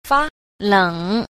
10. 發冷 – fā lěng – phát lãnh (phát lạnh)